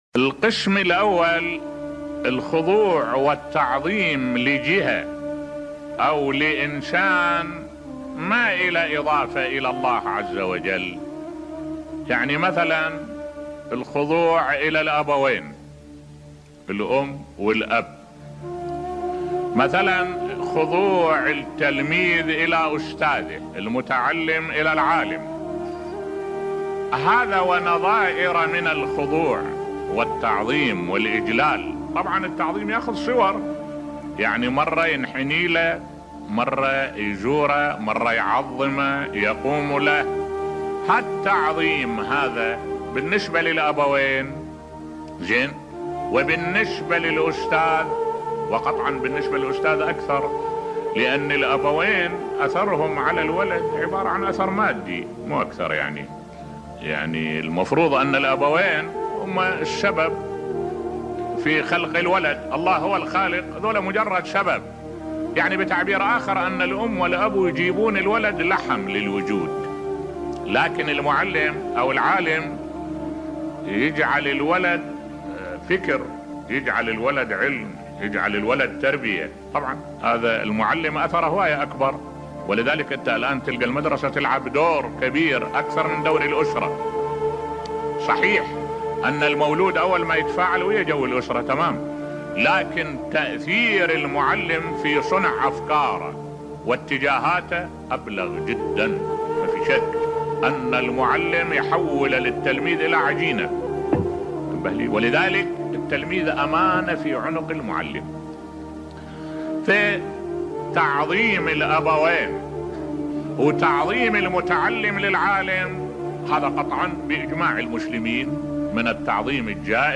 ملف صوتی تعظيم الاستاذ بصوت الشيخ الدكتور أحمد الوائلي